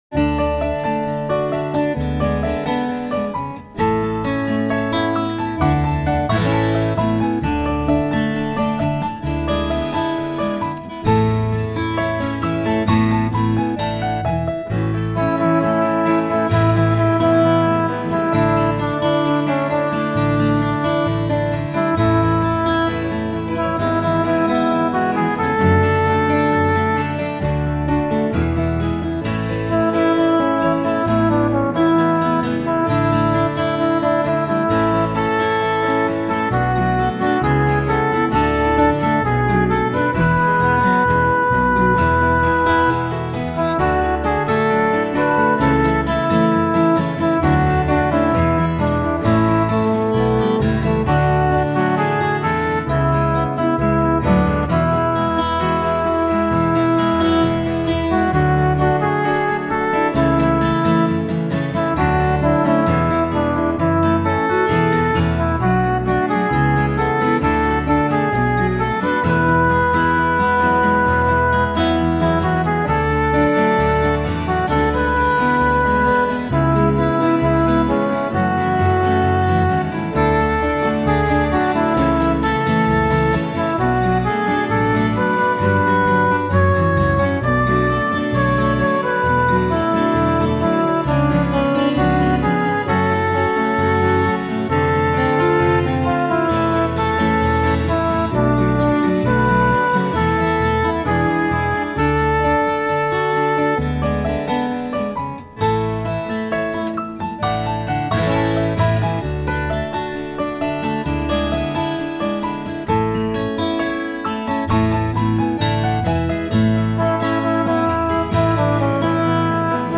My backing has a John Denver vibe about it.